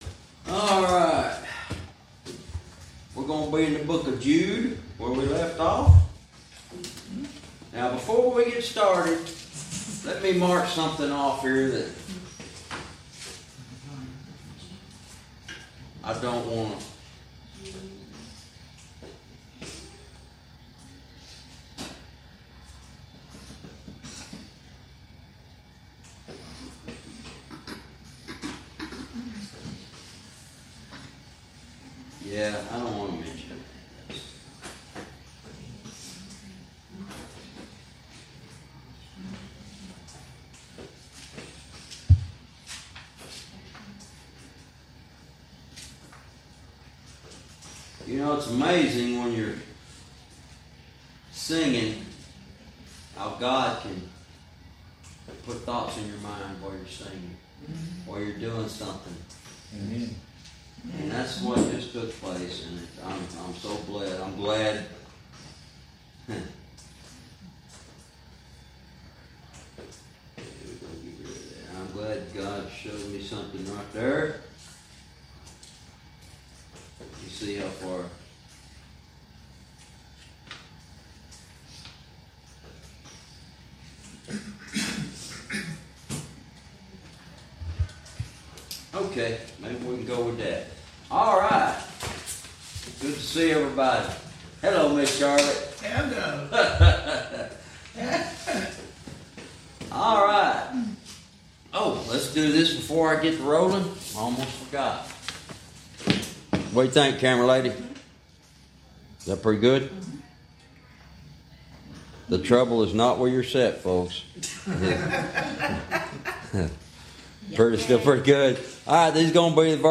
Verse by verse teaching - Jude Lesson 58 Verse 14